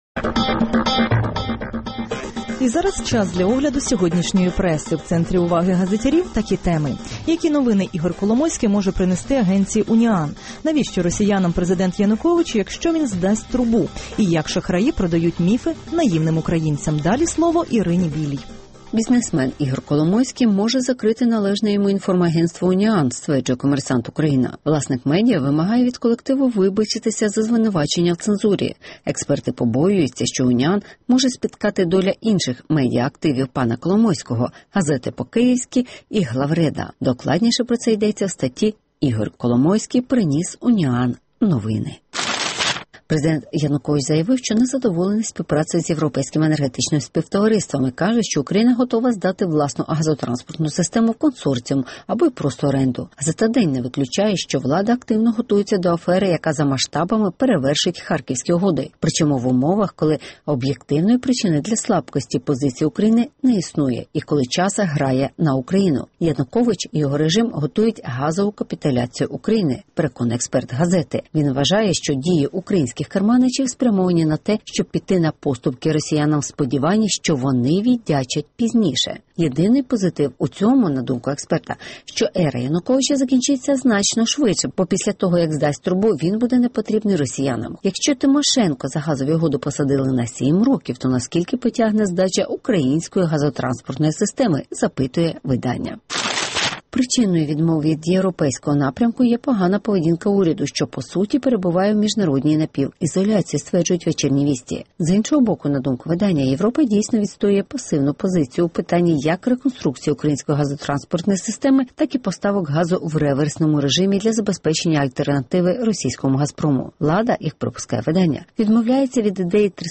Коломойський закриє УНІАН? (Огляд преси)